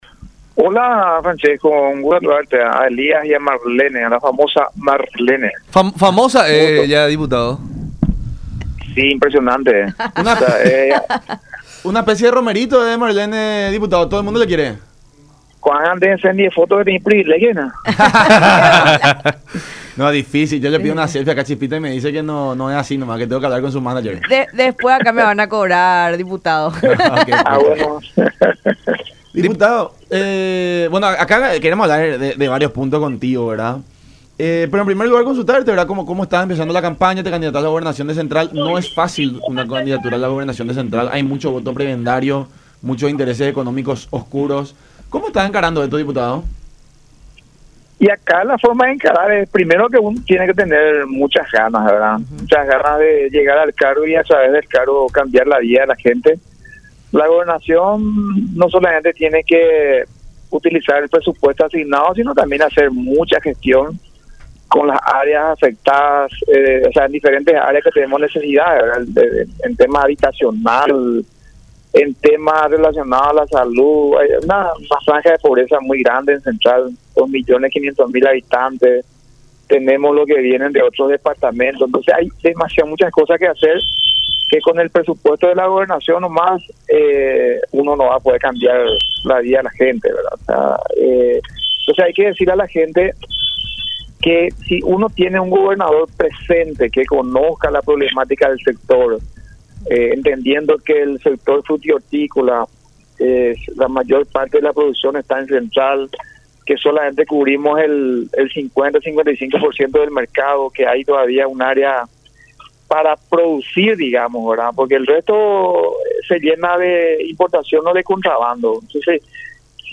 “No tiene que llegar más nadie que esté salpicado de corrupción (…) No tiene que haber ni una pizca de que será igual a Hugo Javier. De lo contrario, los colorados ganarán otra vez”, aseveró Acosta en diálogo con La Unión Hace La Fuerza por Unión TV y radio La Unión, en relación al candidato de la concertación que se enfrentará a la ANR por la gobernación de Central en abril del 2023.